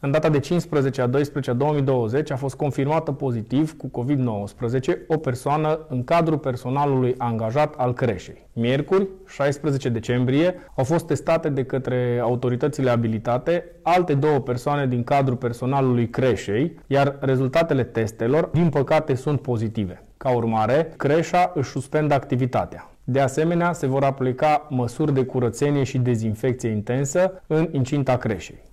Pe primarul Bogdan Pivariu îl puteți asculta aici: